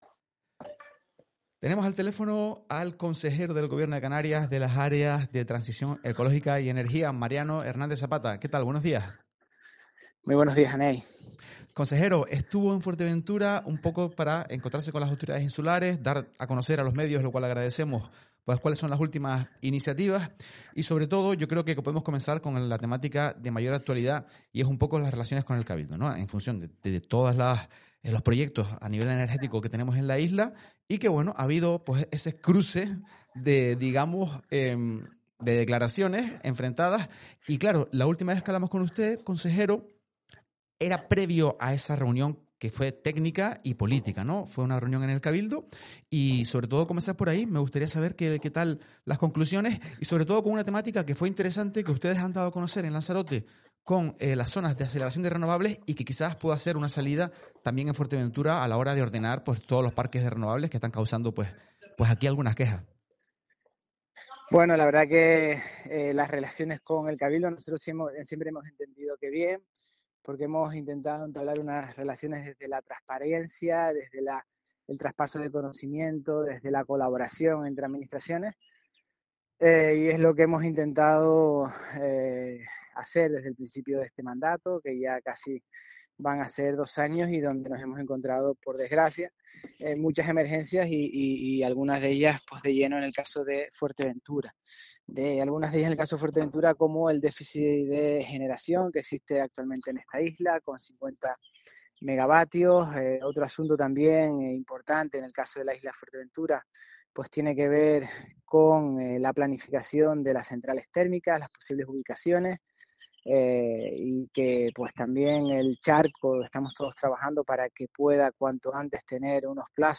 Entrevistas y declaraciones en Fuerteventura Digital Mariano Hernández Zapata | Actualidad Energética en Fuerteventura May 04 2025 | 00:24:00 1x 00:00 / 00:24:00 Subscribe Share